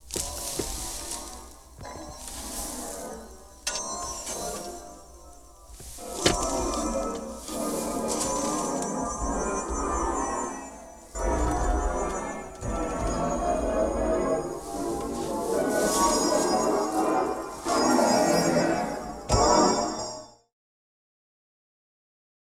Emergence of pitched graduated continuants resembling pitch